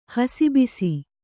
♪ hasi bisi